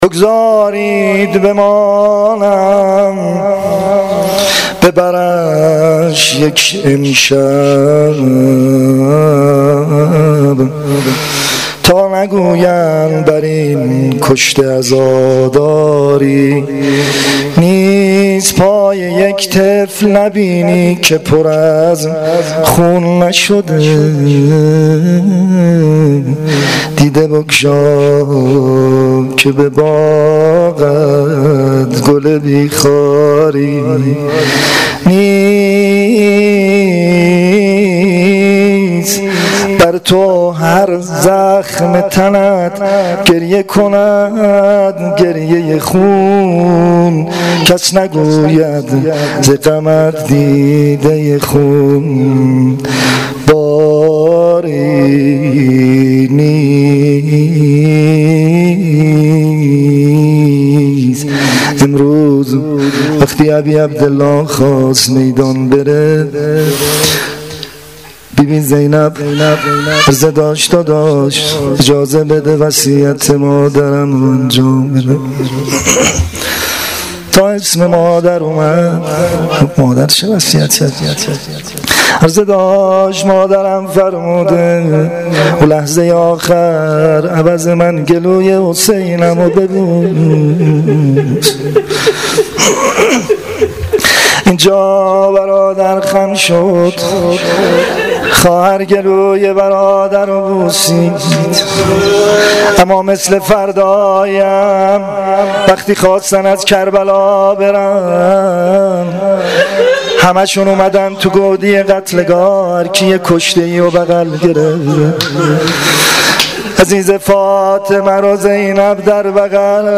روضه پایانی شب یازدهم محرم الحرام 1396 (شام غریبان)
• Shabe11 Moharram1396[05]-Rouzeh Payani.mp3